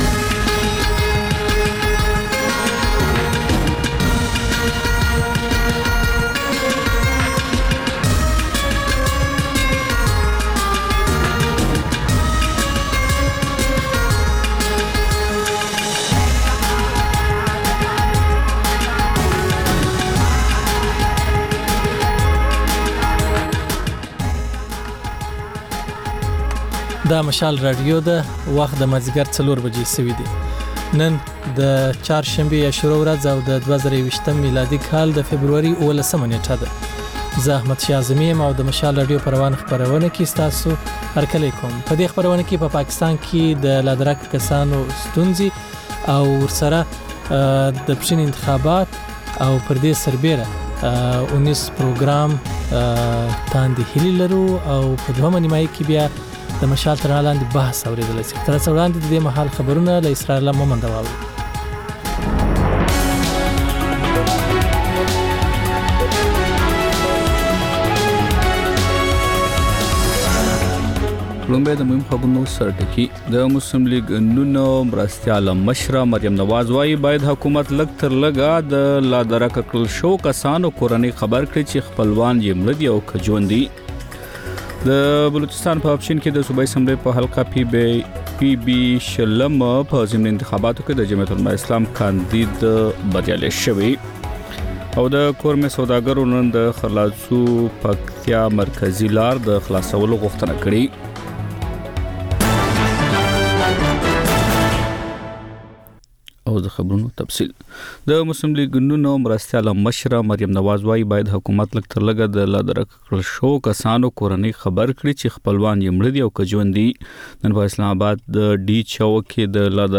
د مشال راډیو مازیګرنۍ خپرونه. د خپرونې پیل له خبرونو کېږي، بیا ورپسې رپورټونه خپرېږي.